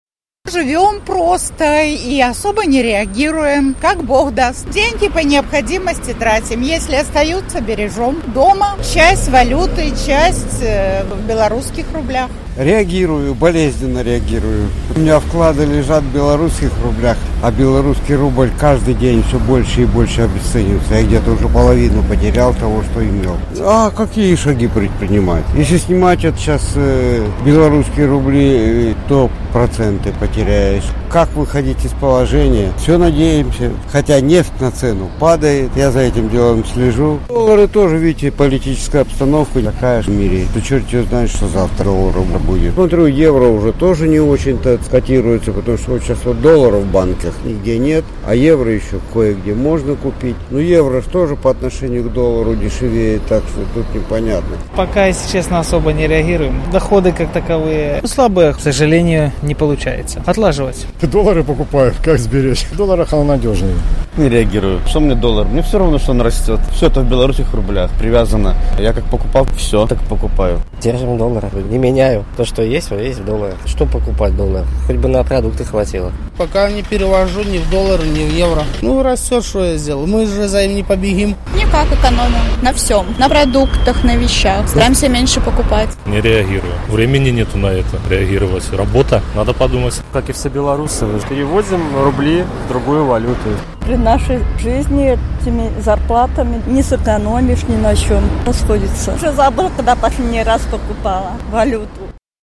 Vox populi
На гэтае пытаньне адказваюць жыхары Гомеля.